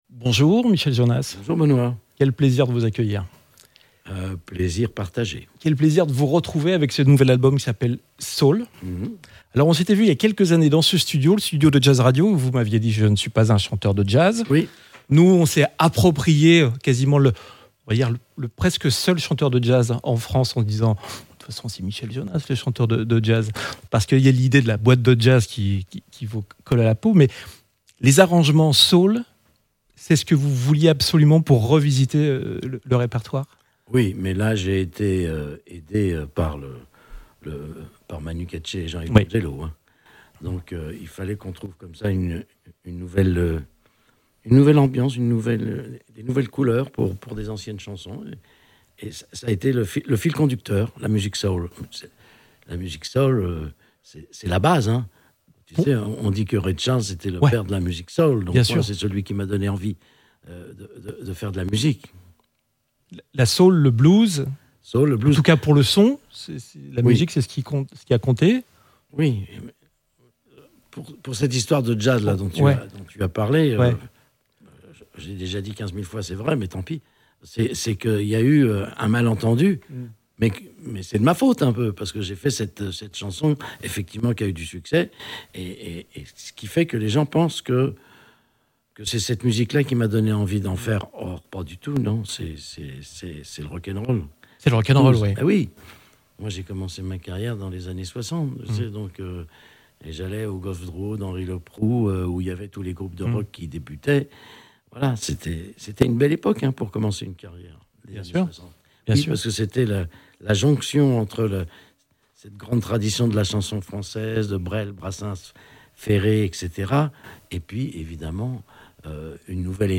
Son interview Docks Live Sessions, au micro de Jazz Radio.